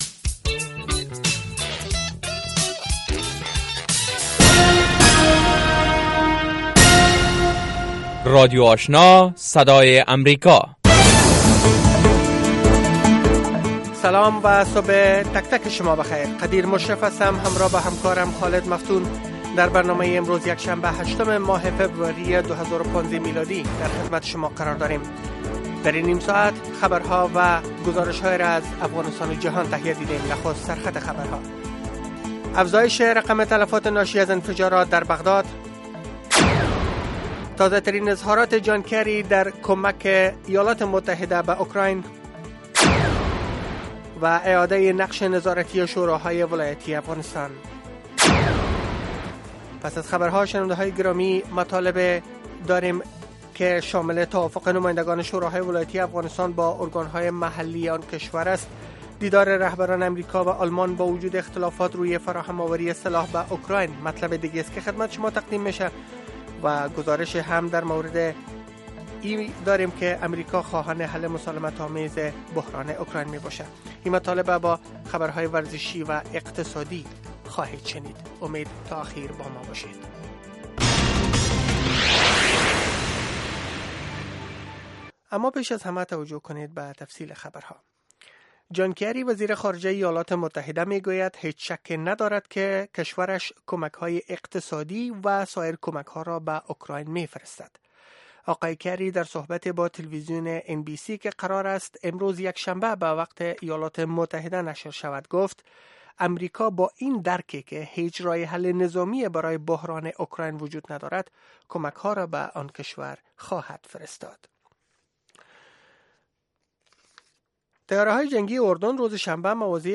دومین برنامه خبری صبح، حاوى تازه ترين خبرهاى افغانستان و جهان است. این برنامه، همچنین شامل گزارش هایی از افغانستان، ایالات متحده امریکا و مطلب مهمی از جهان می باشد. پیش گویی وضع هوای افغانستان و چند رویداد ورزشی از جهان نیز شامل این برنامه است.